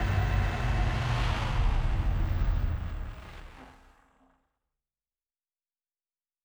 pgs/Assets/Audio/Sci-Fi Sounds/Mechanical/Engine 7 Stop.wav at 7452e70b8c5ad2f7daae623e1a952eb18c9caab4
Engine 7 Stop.wav